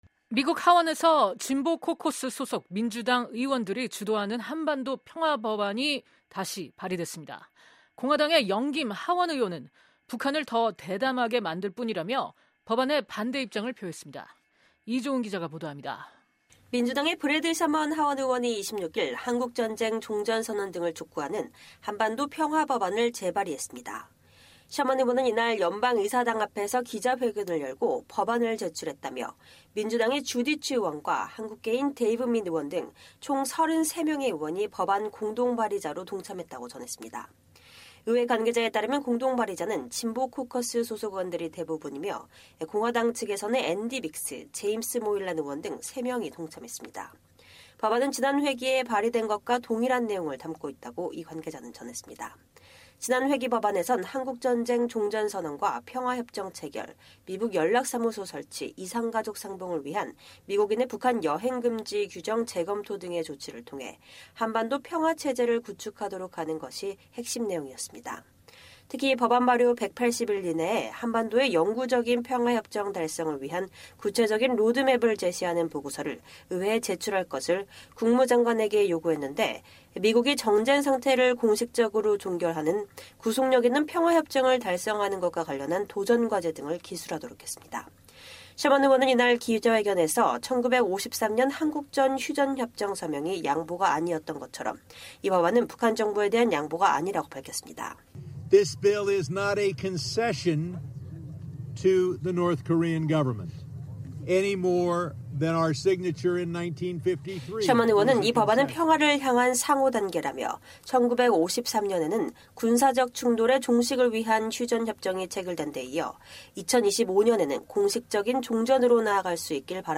[녹취:셔먼 의원] “This bill is not a concession to the North Korean government, any more than our signature in 1953 was a concession.